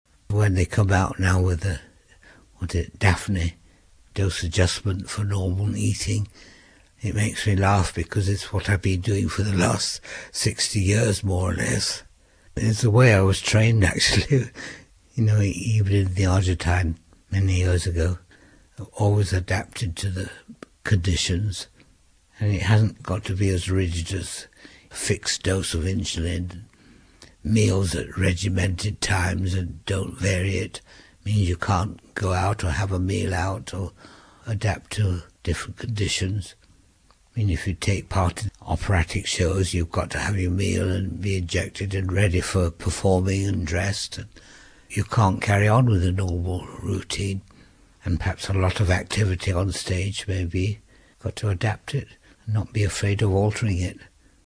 Interview 28